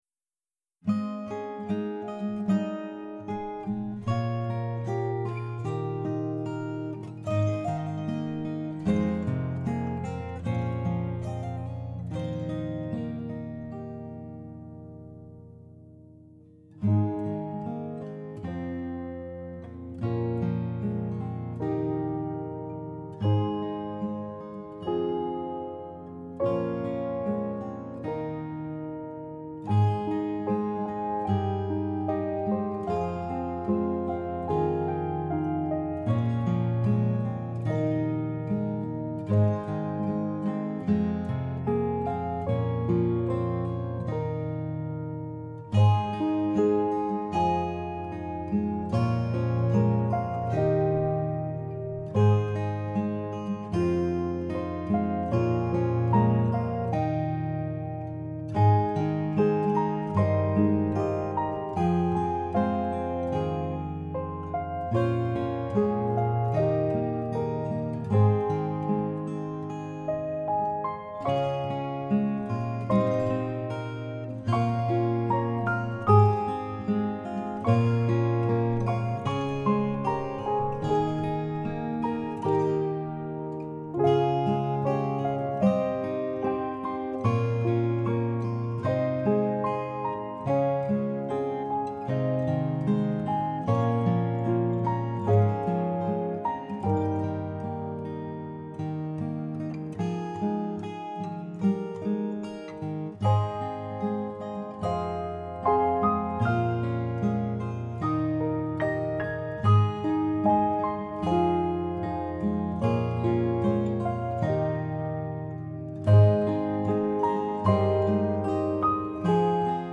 Only Tears Guitar & Piano 2019